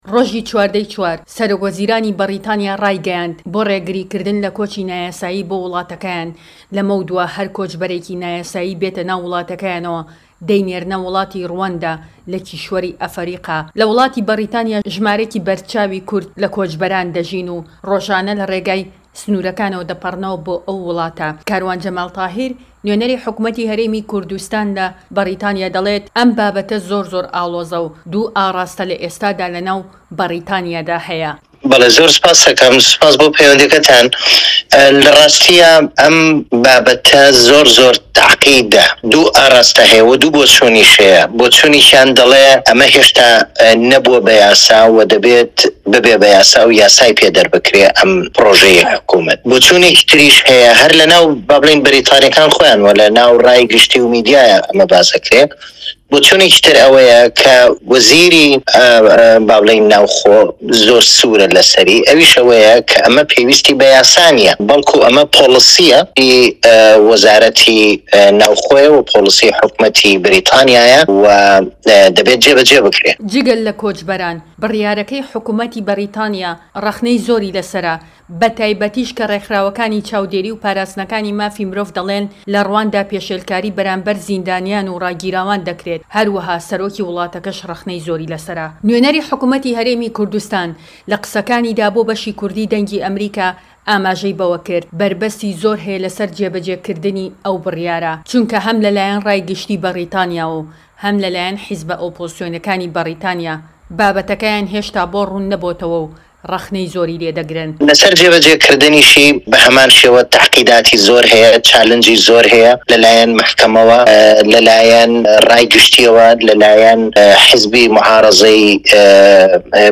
کاروان جەمال تاهیر نوێنەری حکومەتی هەرێم لە بەریتانیا
دەقی قسەکانی جەمال تاهیر لەم ڕاپۆرتەدا Apr 27-022